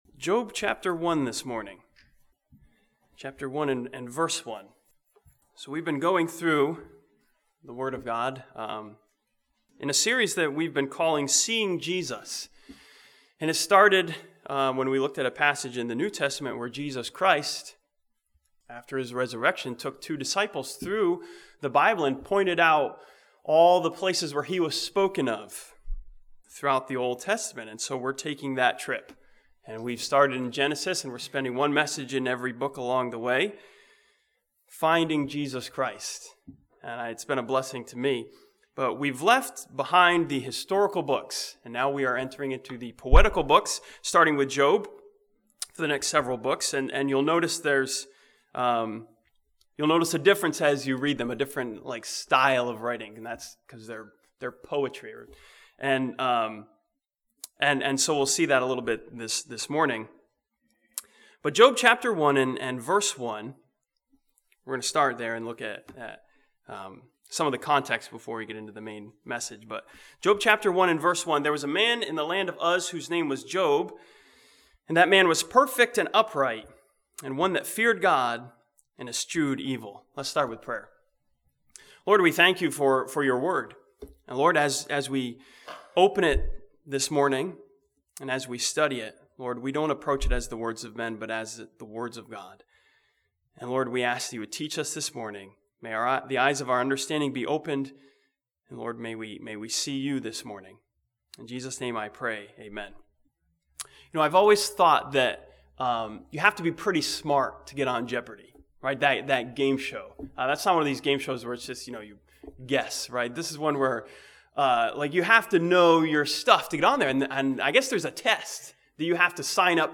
This sermon from Job chapter 33 sees Jesus as our messenger who speaks to us of God's uprightness and deliverance.